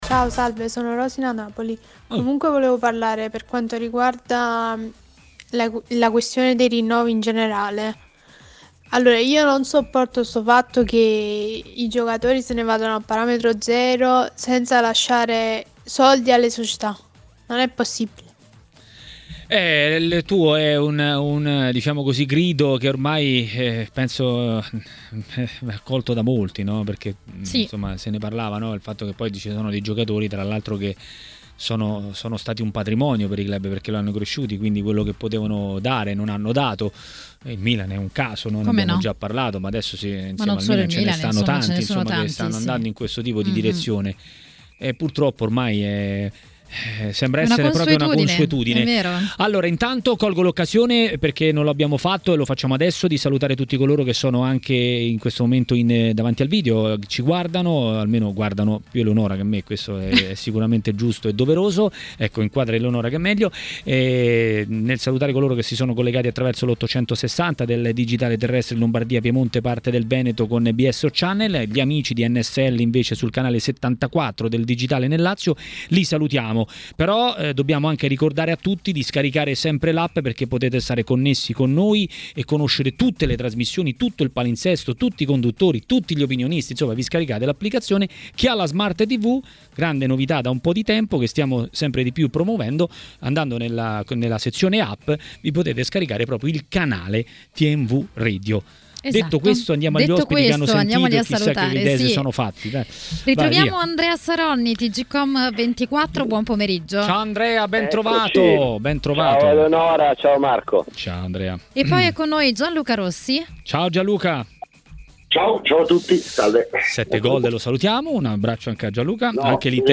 registrazione di TMW Radio